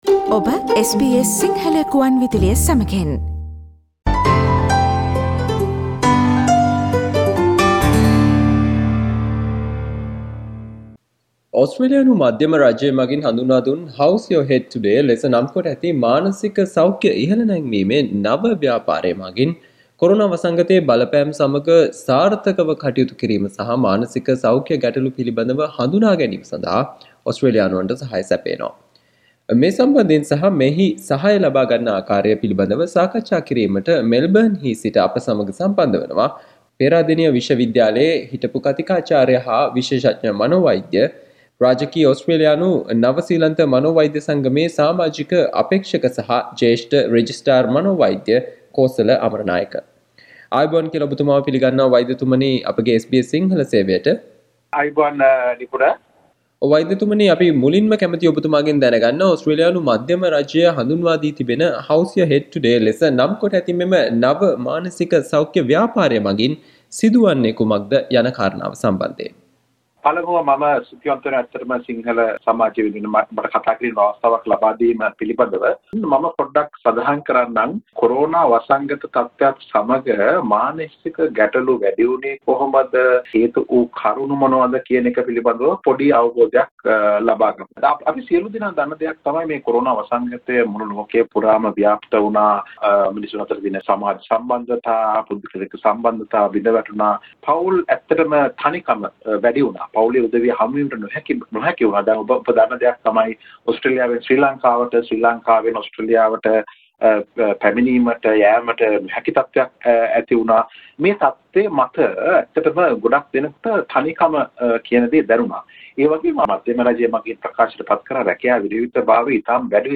SBS Sinhala discussion on How Do You Support Your Mental Health by the programme How’s Your Head Today?